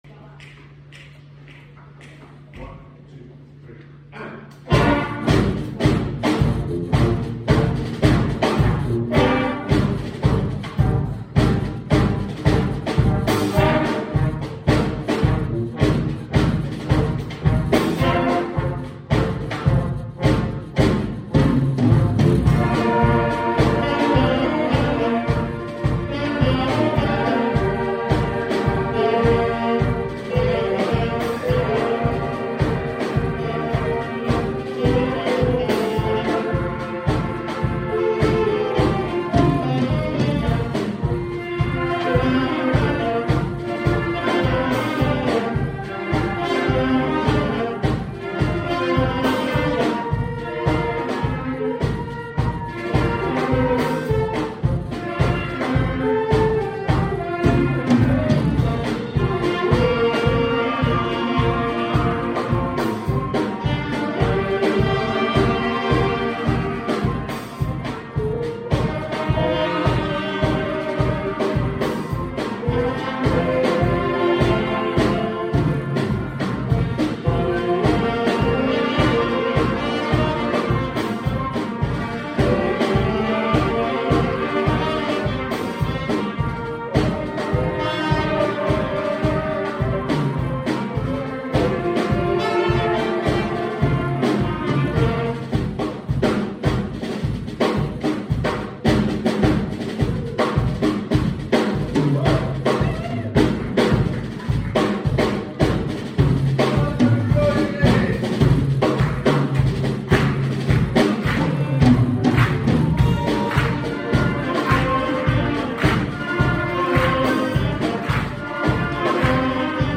Jeruselema | Larch Wind and Brass